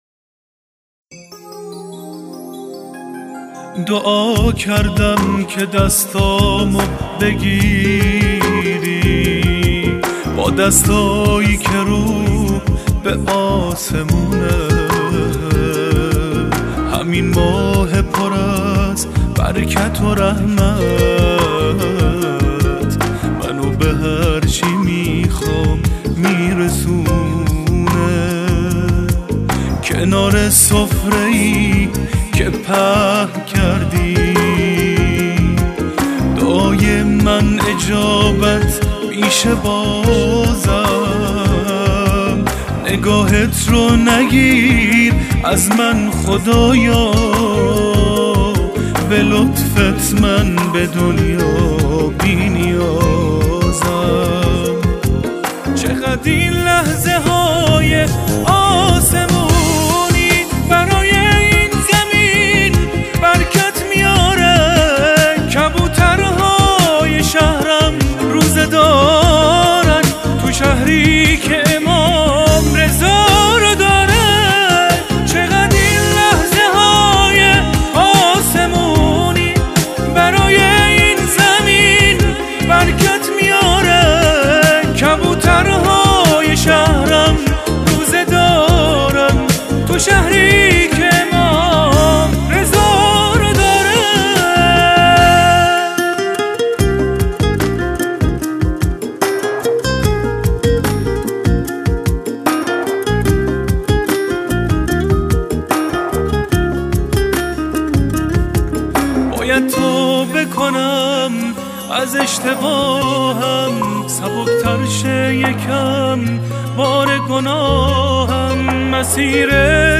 қўшиғи
Эрон мусиқаси